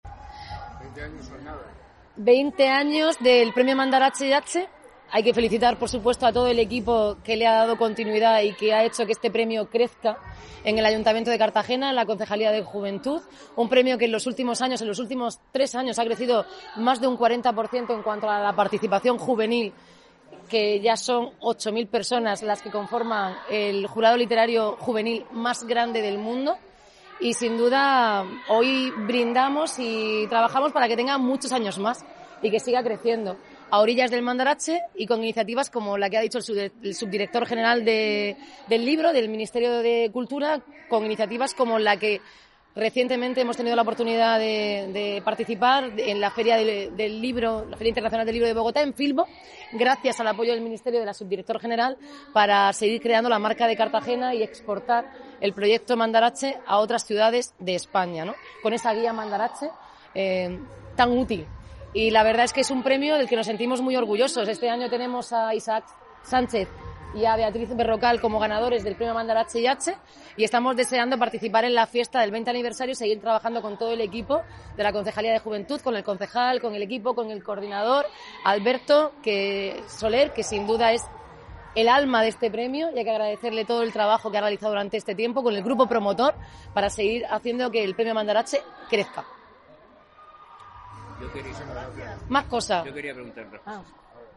Enlace a Declaraciones de la alcaldesa, Noelia Arroyo, gala de Premios Mandarache